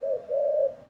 bird_pigeon_call_01.wav